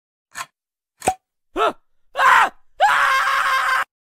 Play, download and share the mysterious light switch original sound button!!!!